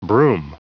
Prononciation du mot brume en anglais (fichier audio)
Prononciation du mot : brume